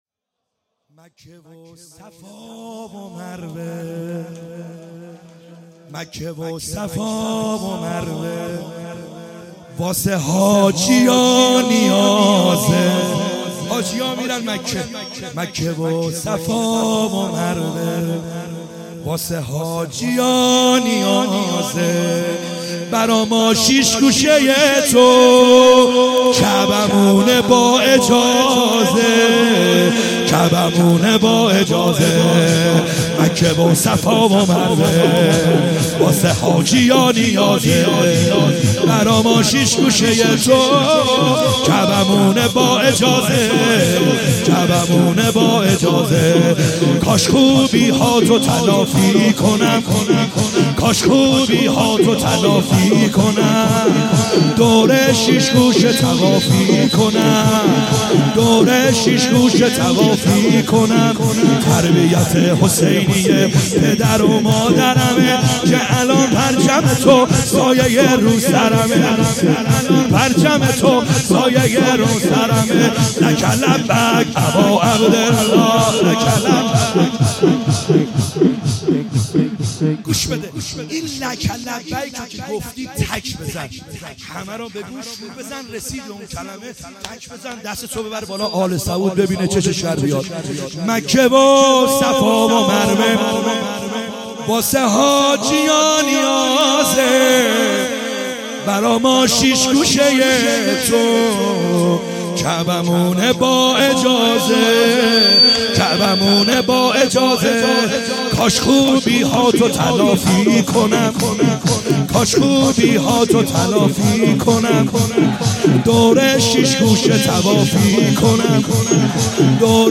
خیمه گاه - بیرق معظم محبین حضرت صاحب الزمان(عج) - شور | مکه و صفا و مروه